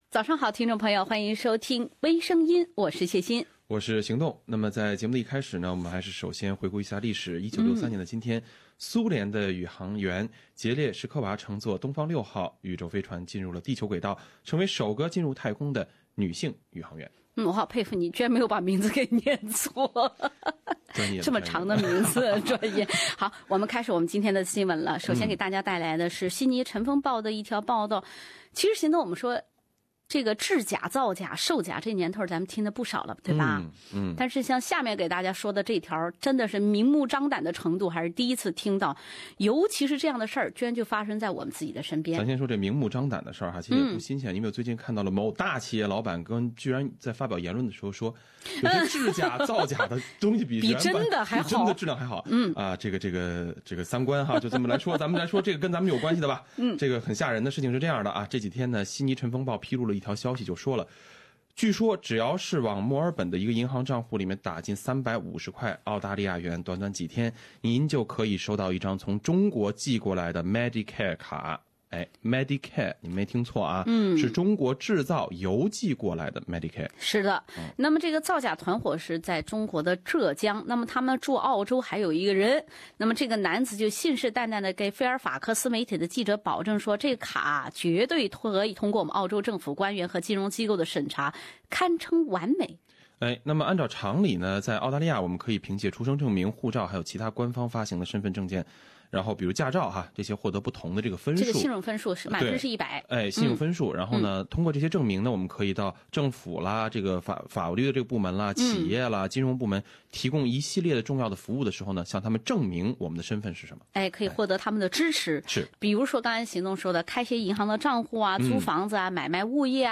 另類輕松的播報方式，深入淺出的辛辣點評；包羅萬象的最新資訊；傾聽全球微聲音。